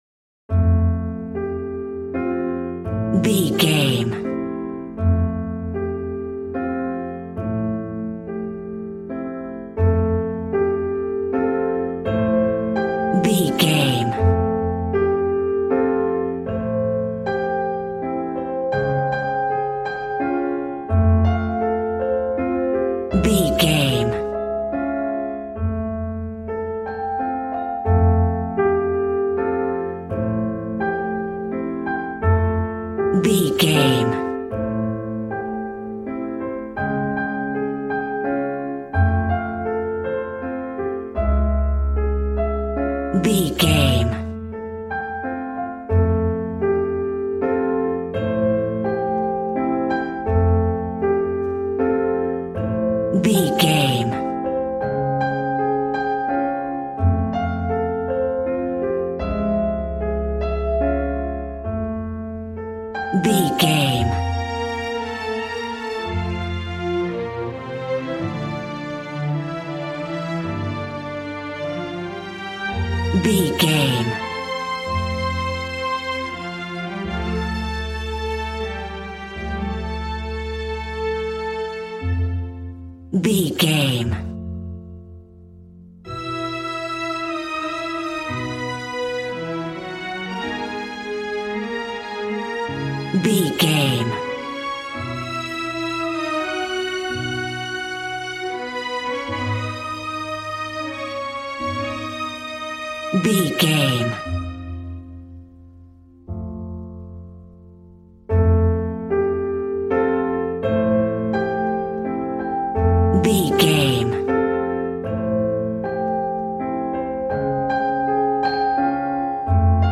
Regal and romantic, a classy piece of classical music.
Aeolian/Minor
strings
violin
brass